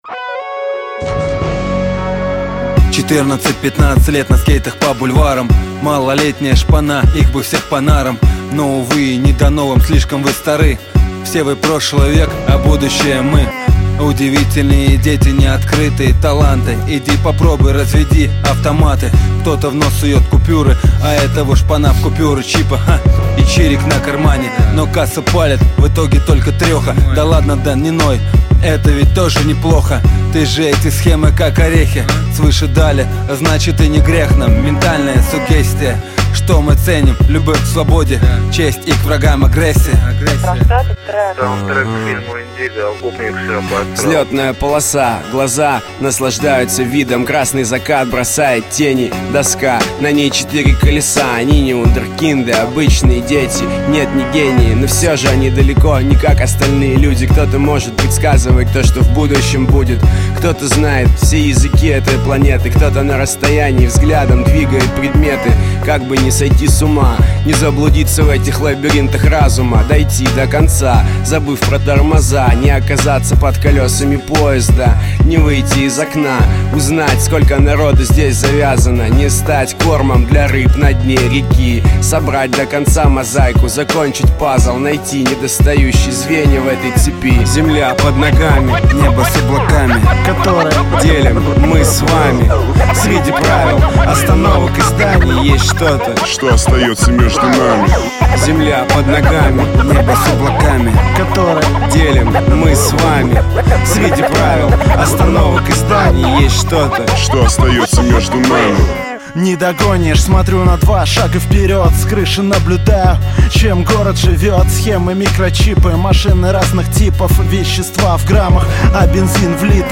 Жанр: Rap